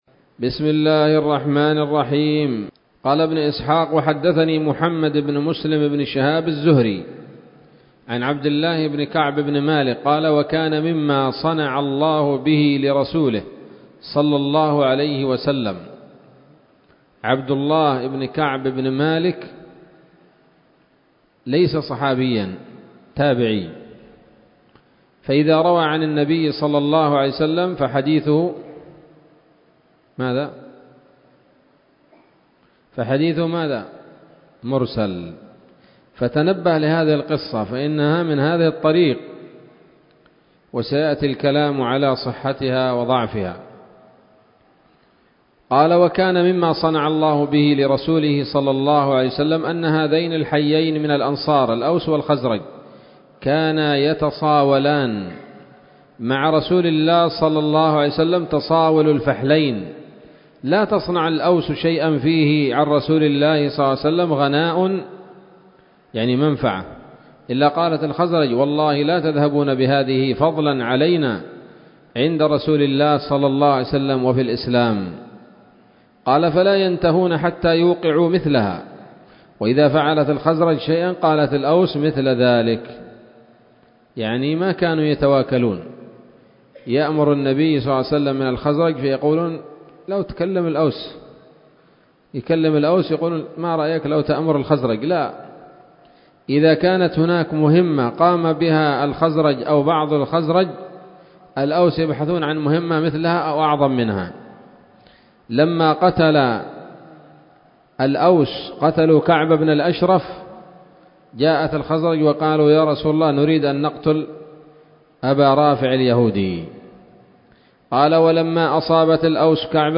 الدرس السابع عشر بعد المائتين من التعليق على كتاب السيرة النبوية لابن هشام